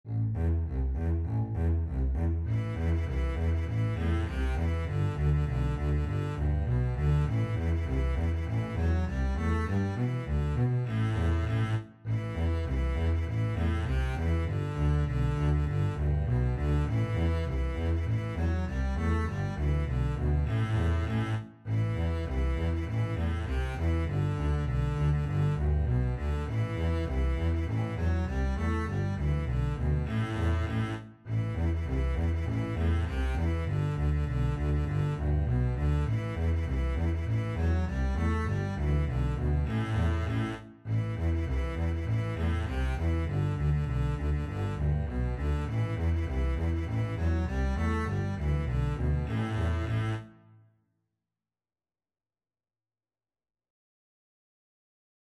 Double Bass Duet version
2/4 (View more 2/4 Music)